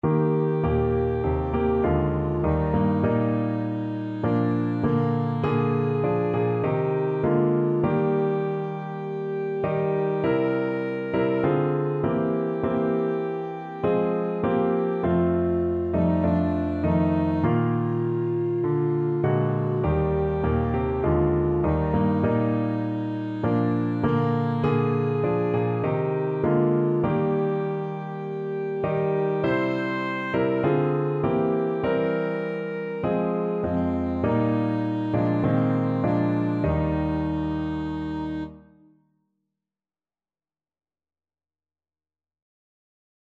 Alto Saxophone version
Christian
4/4 (View more 4/4 Music)
Classical (View more Classical Saxophone Music)